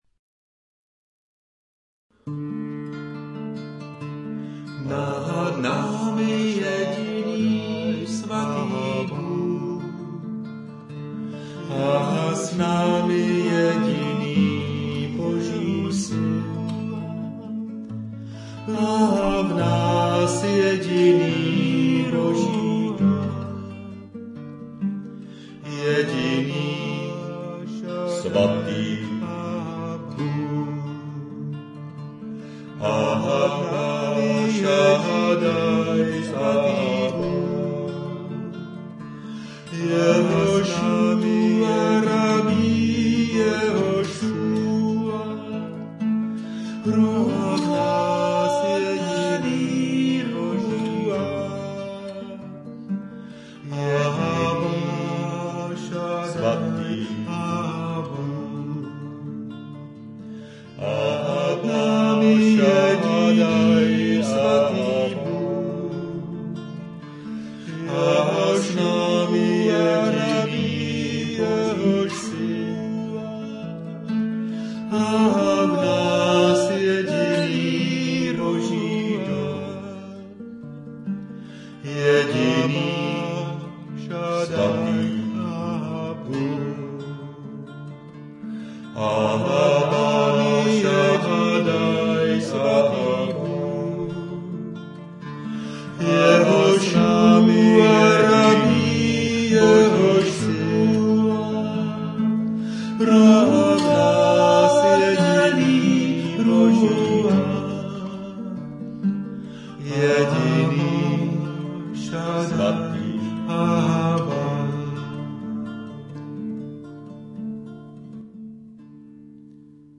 Po letech zase kánon!
Demo